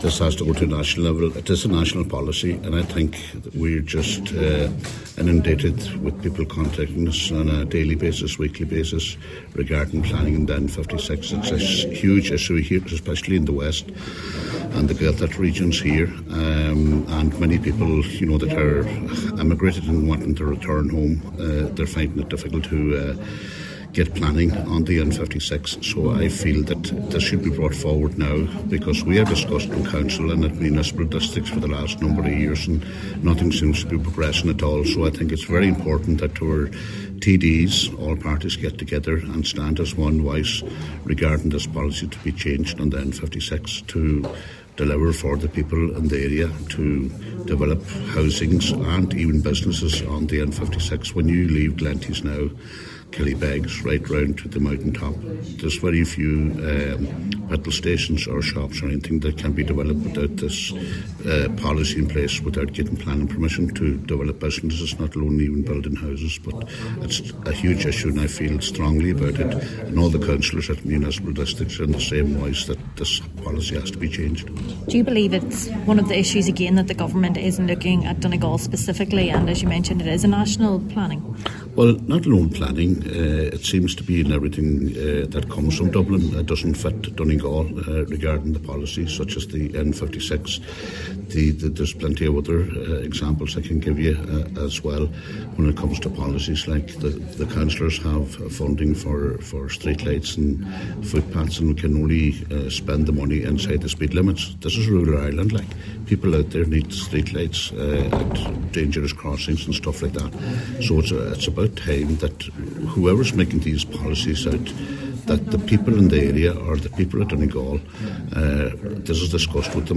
Cathaoirleach of the Glenties Municipal District, Councillor John Sheamais O’Ferraigh says they have been left with no other option but to seek national intervention……